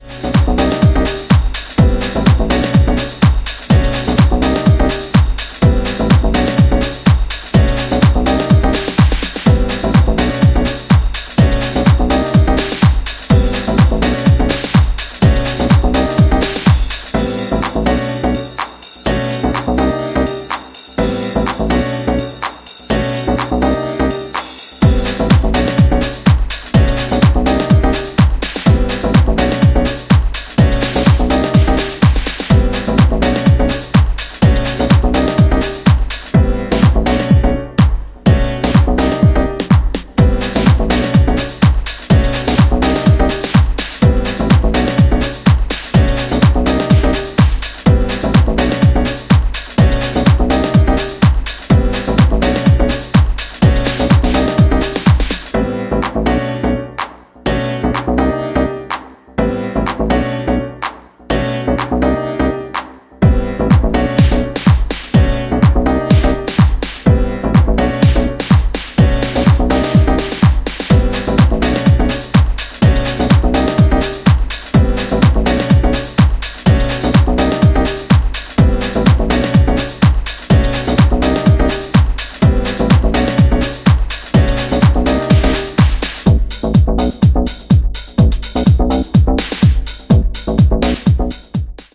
channelling gospel house influence in varying forms